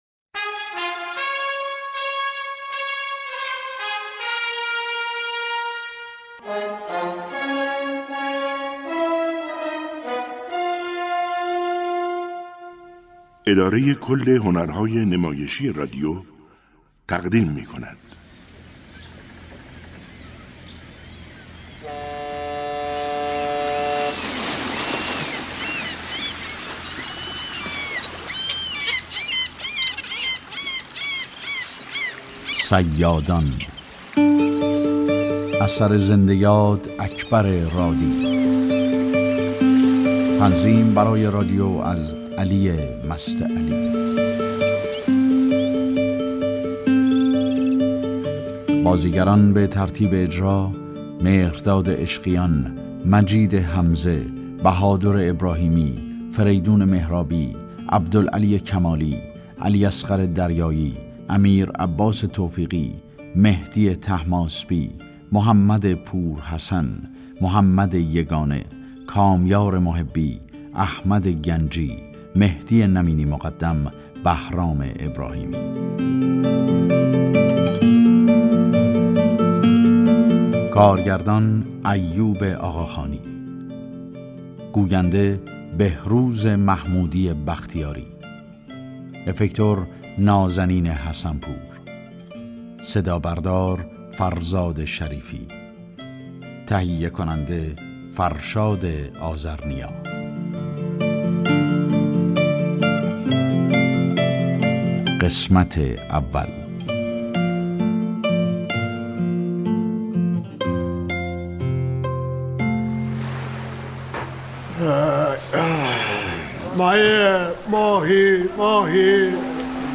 نمایش رادیویی